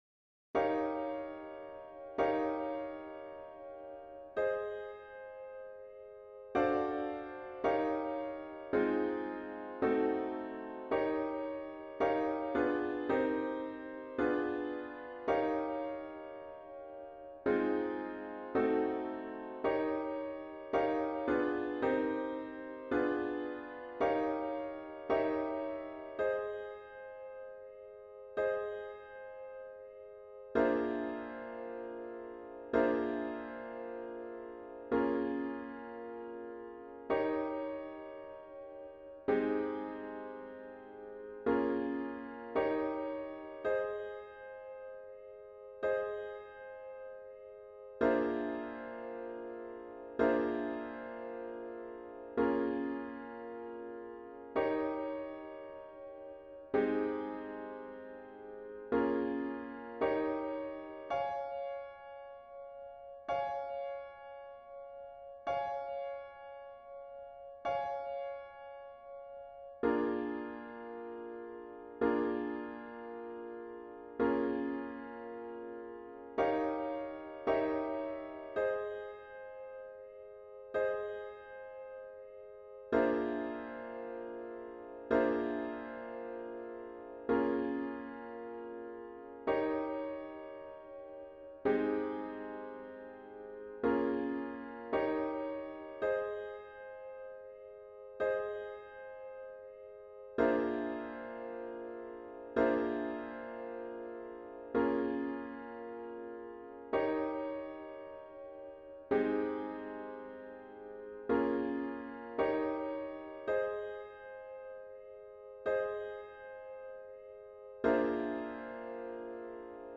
A chords only version of the score is found here.